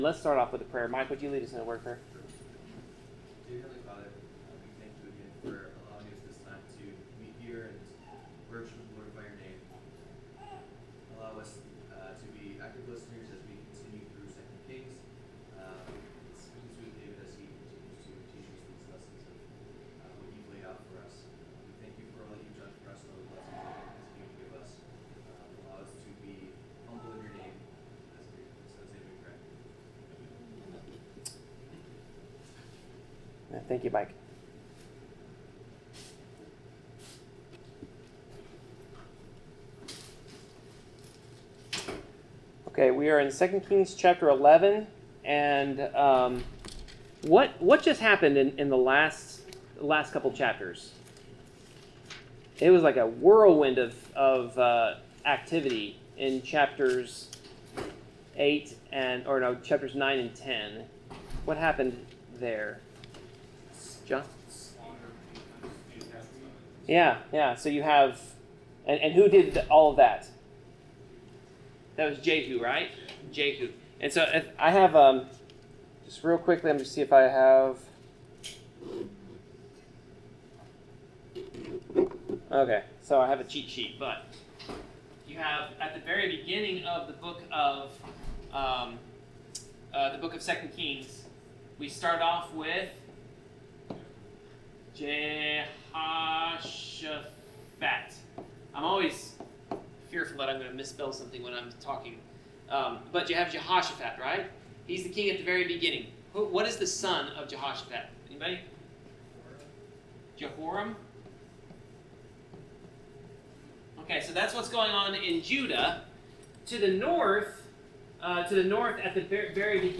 Bible class: 2 Kings 11-12
Passage: 2 Kings 11-12 Service Type: Bible Class